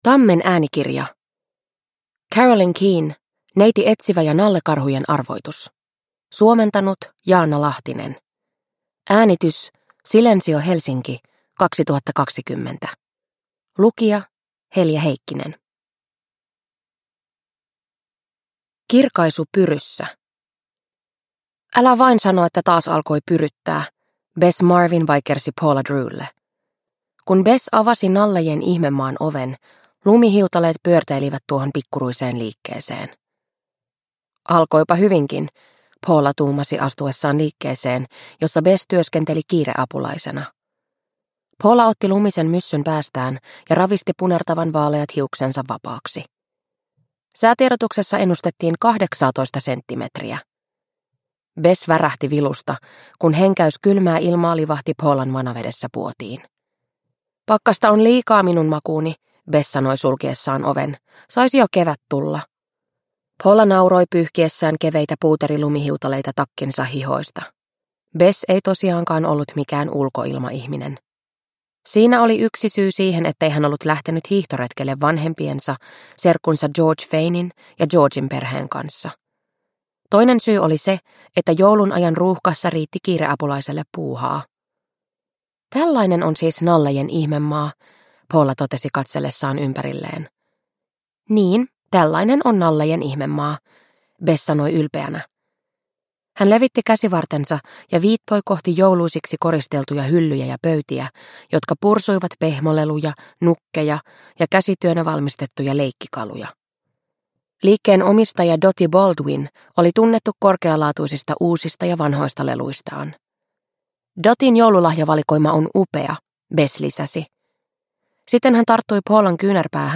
Neiti Etsivä ja nallekarhujen arvoitus – Ljudbok – Laddas ner